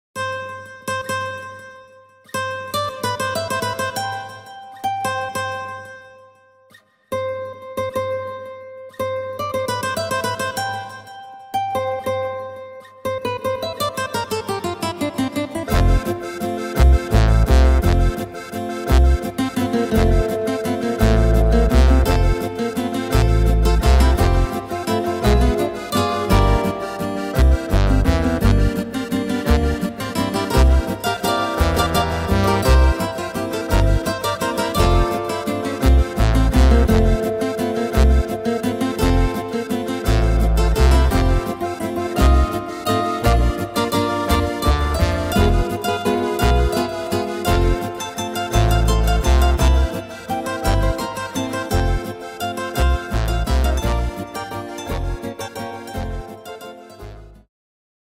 Tempo: 100-170 / Tonart: F-Dur